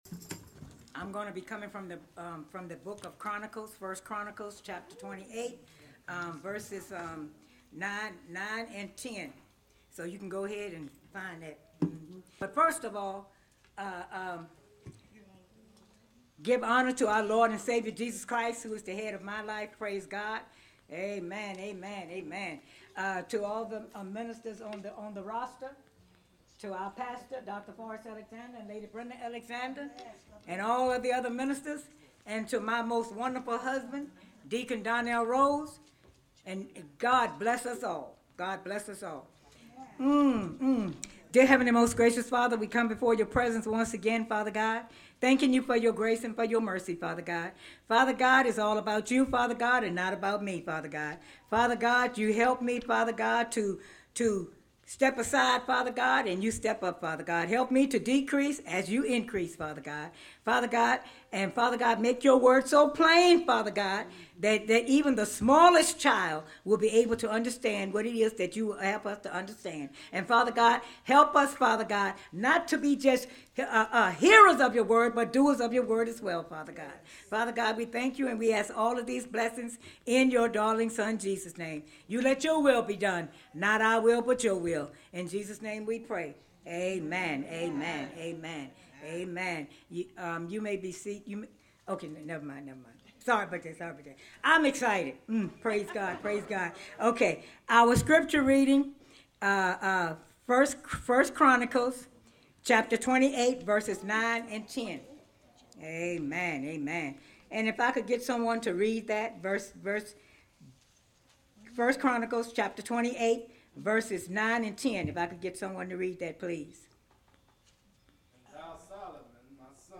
1 Chronicles 8:9 Share this sermon: